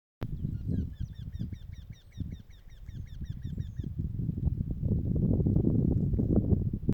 Tītiņš, Jynx torquilla
Administratīvā teritorijaAlūksnes novads
StatussDzied ligzdošanai piemērotā biotopā (D)
Piezīmes/dzied jau 2 dienas